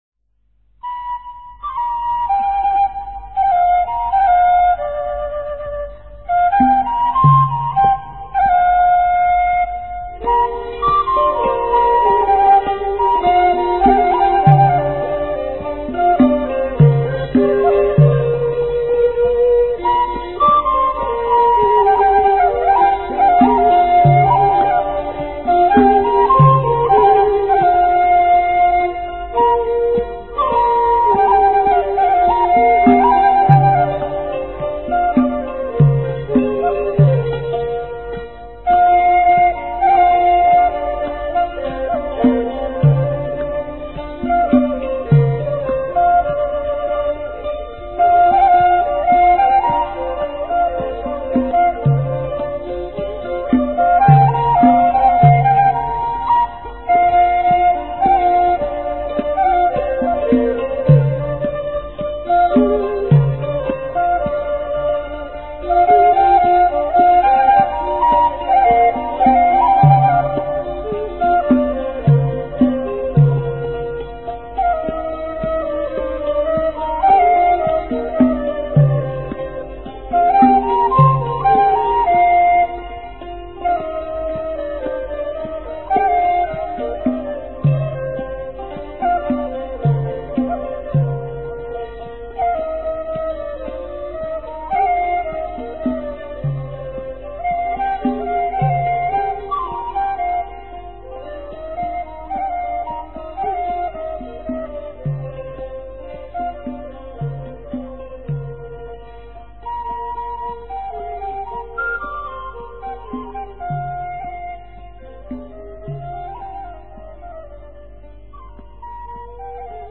prayer.mp3